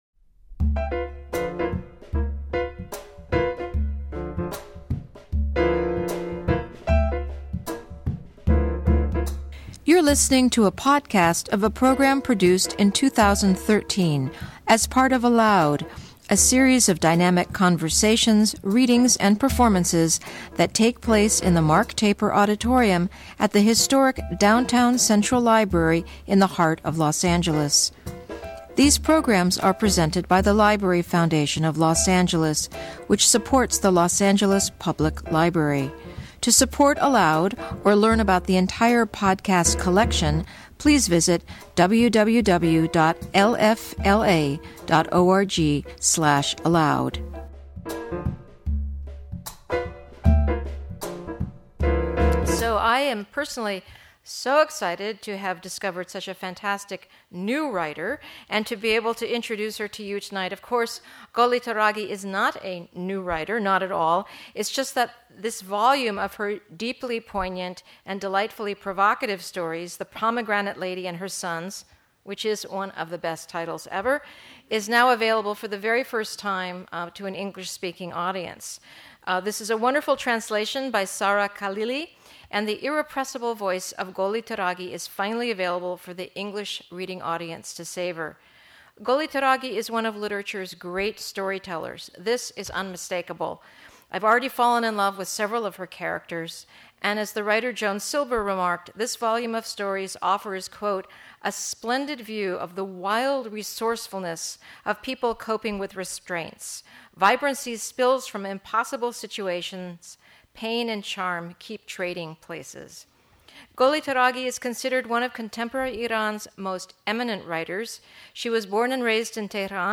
Listen in as Taraghi shares from The Pomegranate Lady and Her Sons, made fully accessible to the English-speaking audience for the first time.
Goli Taraghi In Conversation With author Reza Aslan